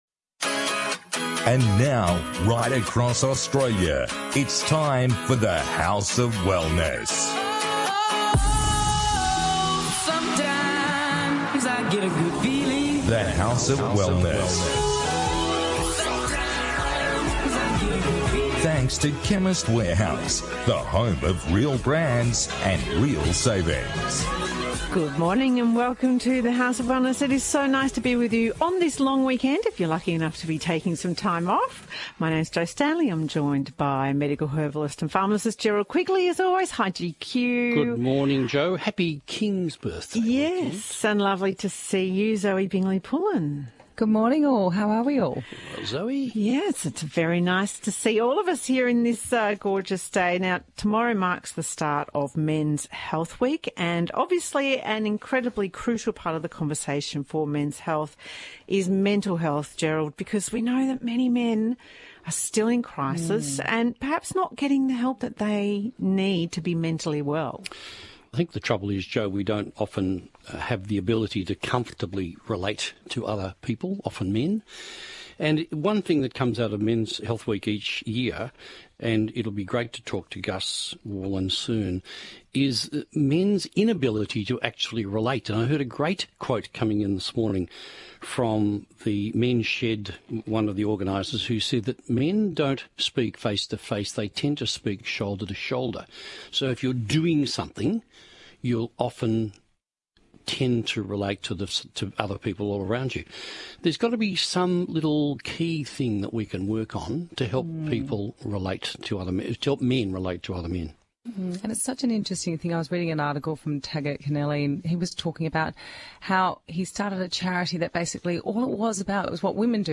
The House of Wellness radio show